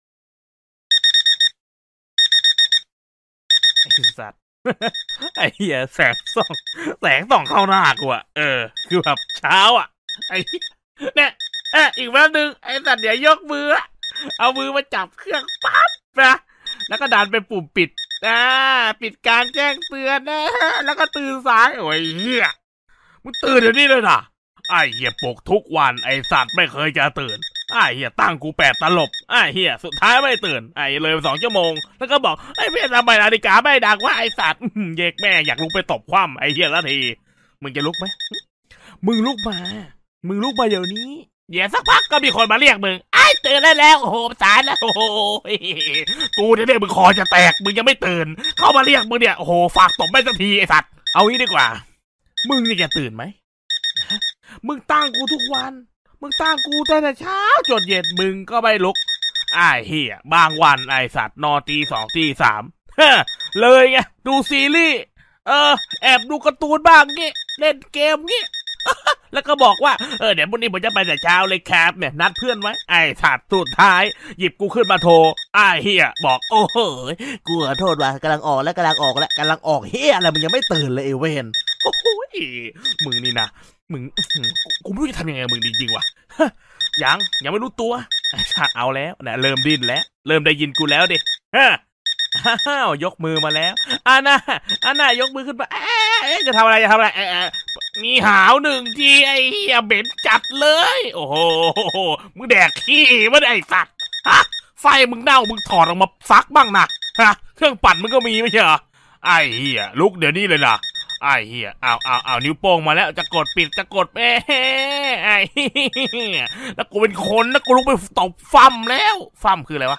เสียงปลุก… กวนๆ
หมวดหมู่: เสียงเรียกเข้า